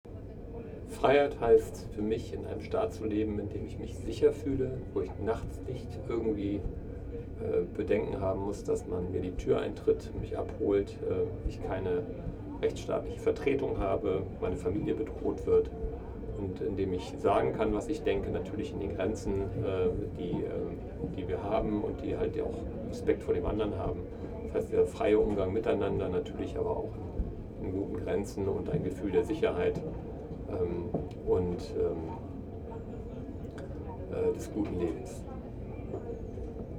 Standort der Erzählbox:
Ein Fest für die Demokratie @ Bundeskanzleramt, Berlin